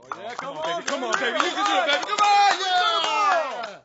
Crowd02-rolling.wav